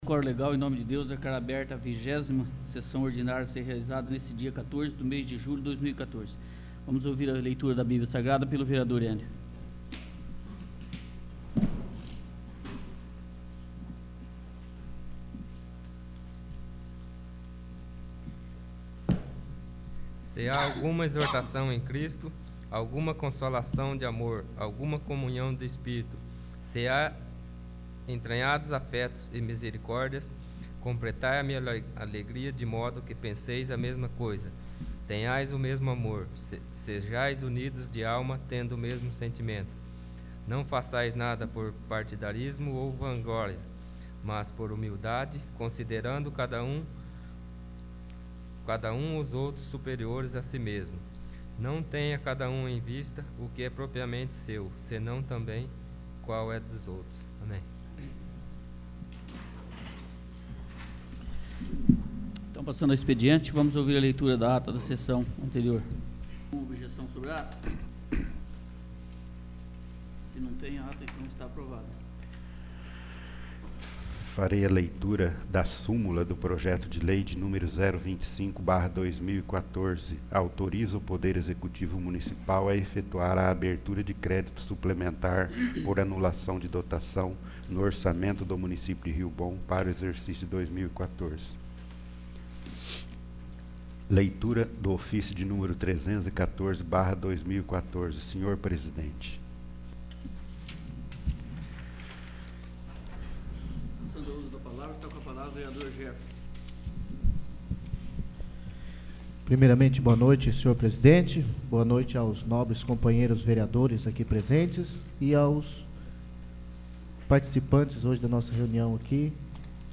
20º. Sessão Ordinária